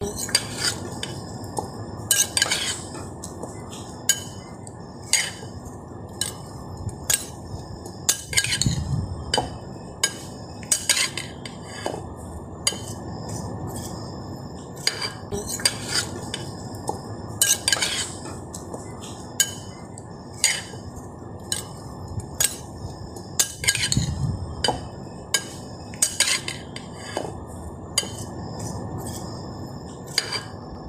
Tiếng Trộn thức ăn trong tô sứ, chén thủy tinh…
Thể loại: Tiếng ăn uống
Description: Âm thanh đảo thức ăn, tiếng muỗng chạm bát, tiếng thìa khuấy, hiệu ứng âm thanh bếp núc, sound effect nhà bếp, âm va chạm dụng cụ ăn uống.
tieng-tron-thuc-an-trong-to-su-chen-thuy-tinh-www_tiengdong_com.mp3